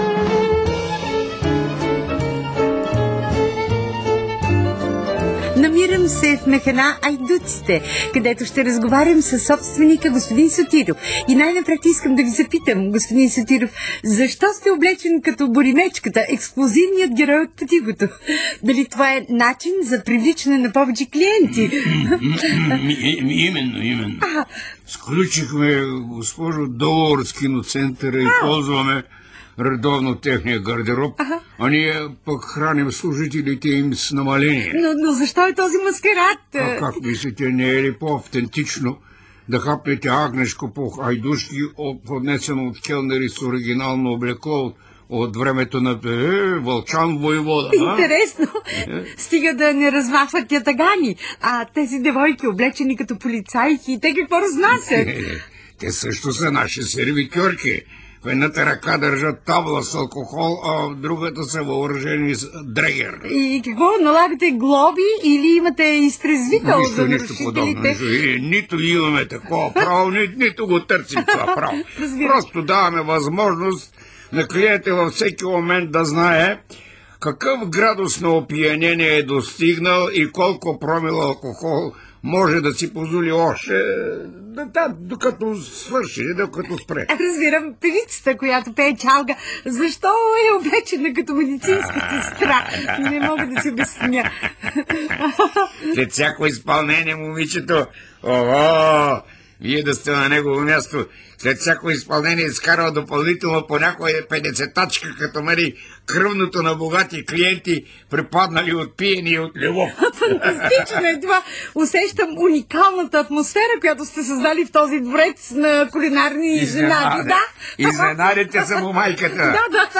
механа_айдуците_--драм.разказ--радио.mp3